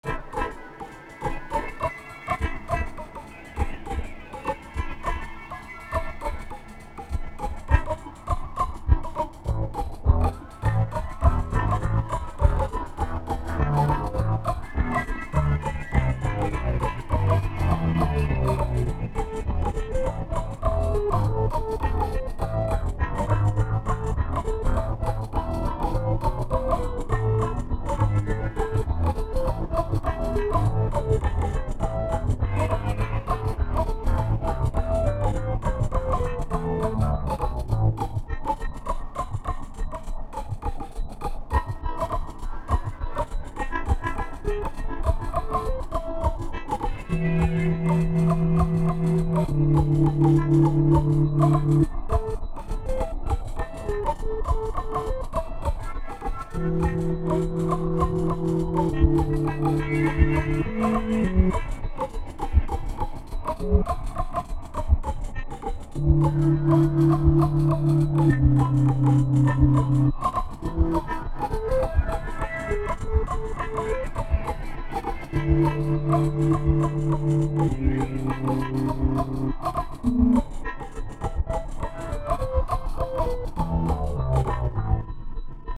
Men heilt okej lirar di väl no på flöjtan siin.